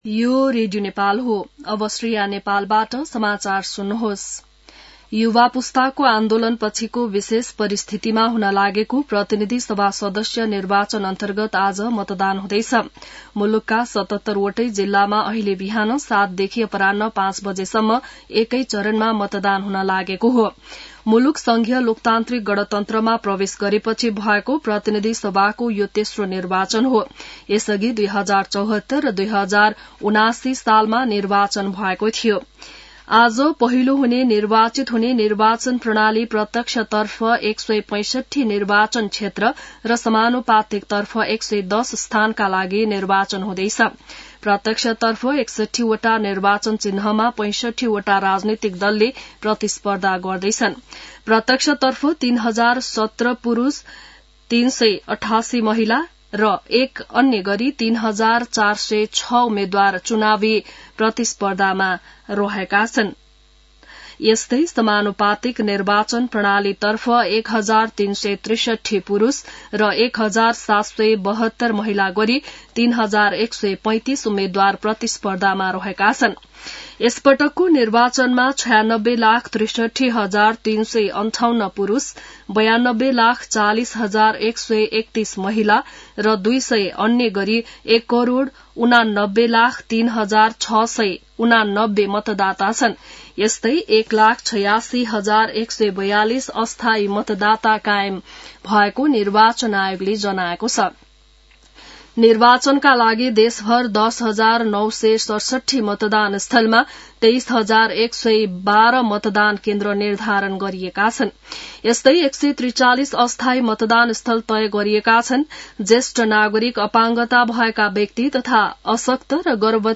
बिहान ६ बजेको नेपाली समाचार : २१ फागुन , २०८२